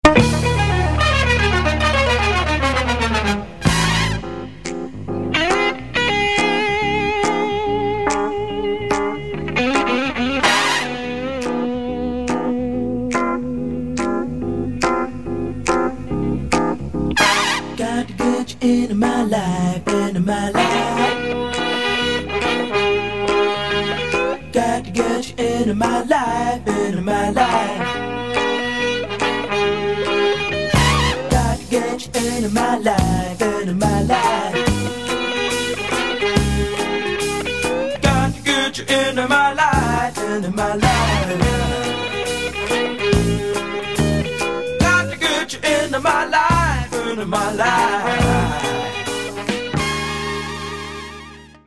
RECORDED AND MIXED AT CHEROKEE STUDIOS, LOS ANGELES